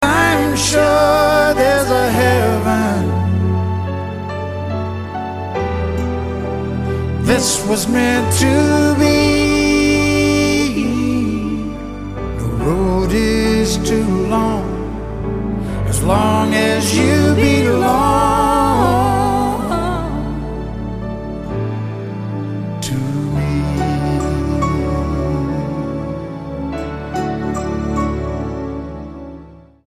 STYLE: Country
Almost entirely ballads